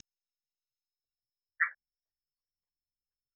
Nahá, Chiapas
Rana arborícola de ojos rojos.
Agalychnis callidryas